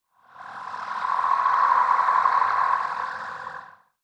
8 bits Elements
Magic Demo
Magic_67_3.wav